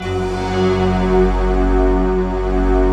SI1 CHIME02L.wav